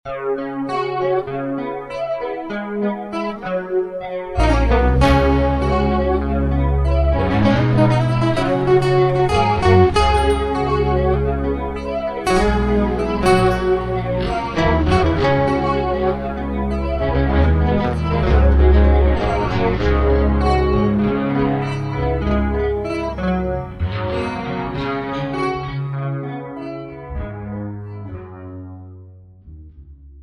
bass. Recorded with the Ovation, it's got a nice chorus and excellent bass line behind it all.